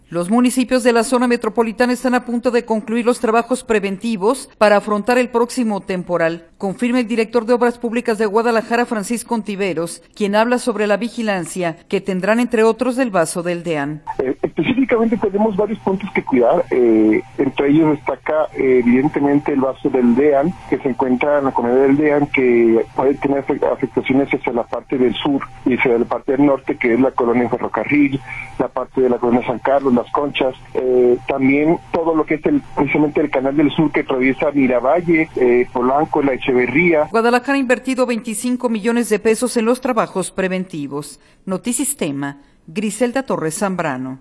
Los municipios de la Zona Metropolitana están a punto de concluir los trabajos preventivos para afrontar el próximo temporal, confirma el director de Obras Públicas de Guadalajara, Francisco Ontiveros, quien habla sobre la vigilancia que tendrán entre otros del vaso del Deán.